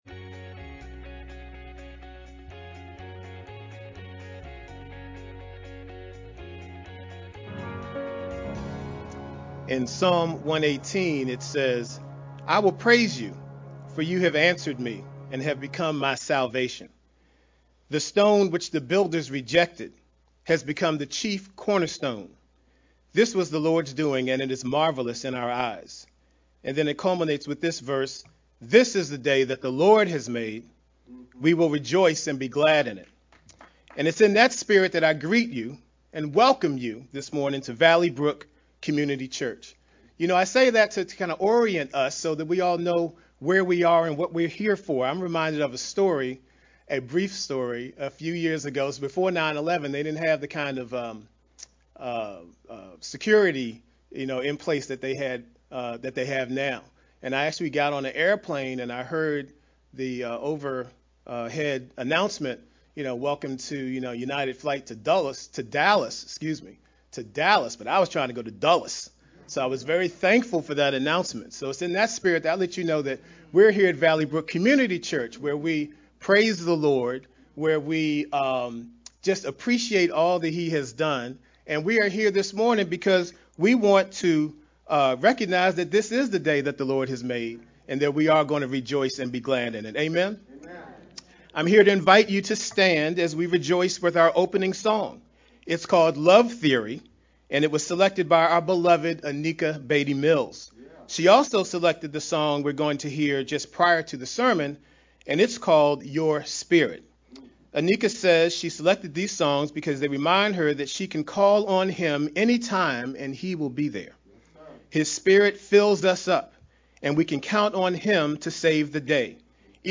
VBCC-Sermon-edited-June-12th-Mp3-CD.mp3